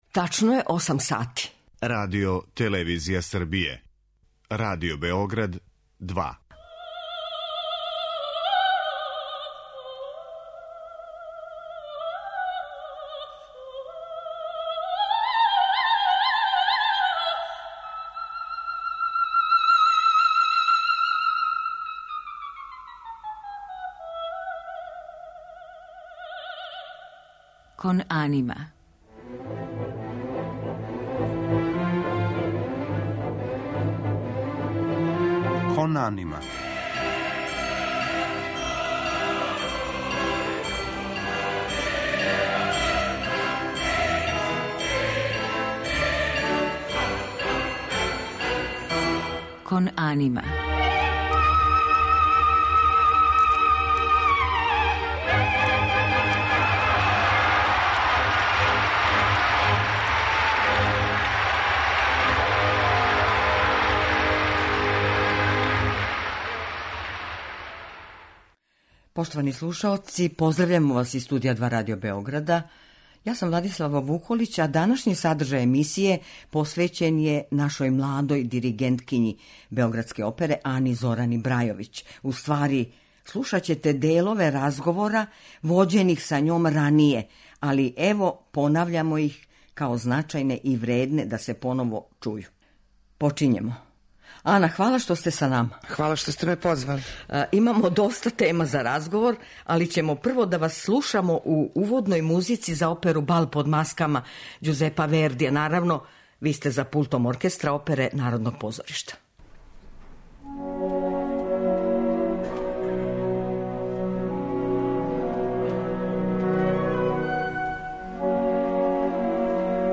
Овом приликом, као значајни и вредни да се поново чују, биће емитовани делови разговора који је снимљен прошле године.
За музички део одабрани су оперски фрагменти у извођењу Оркестра Oпере Народног позоришта, а под диригентском палицом наше данашње гошће.